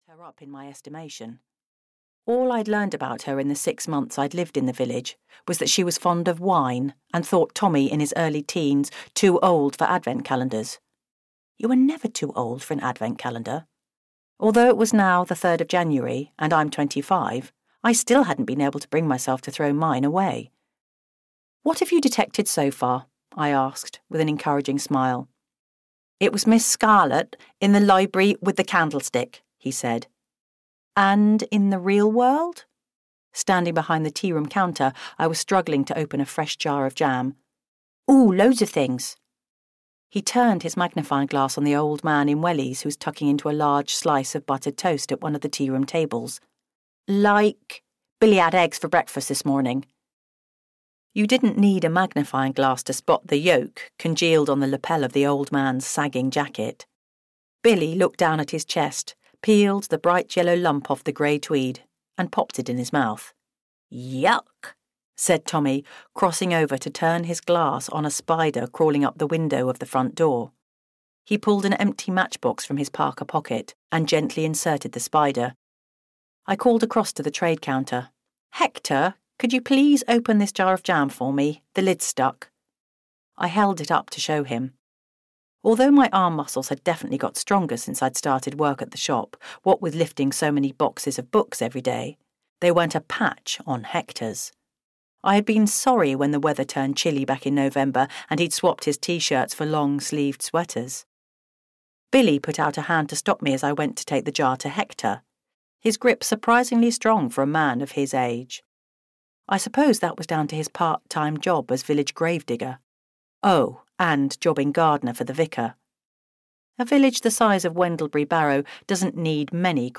Murder at the Well (EN) audiokniha
Ukázka z knihy